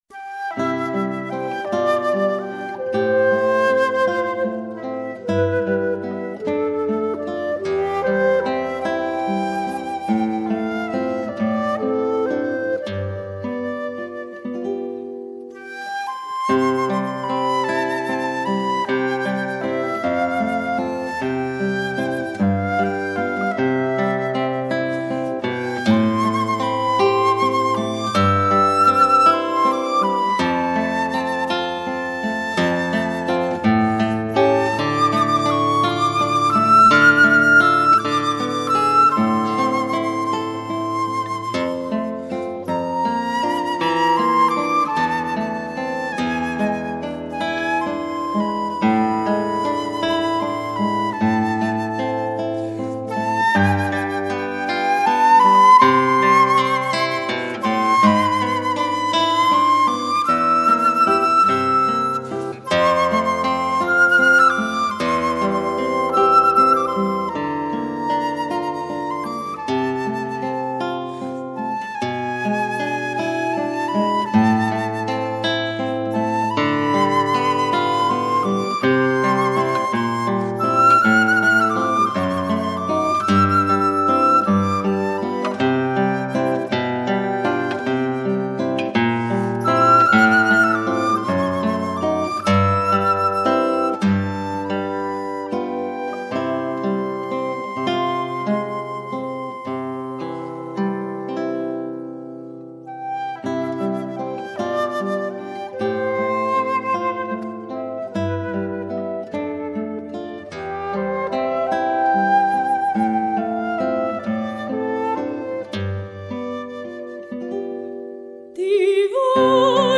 Una canzoncina di S. Alfonso
chitarra
flauto
voce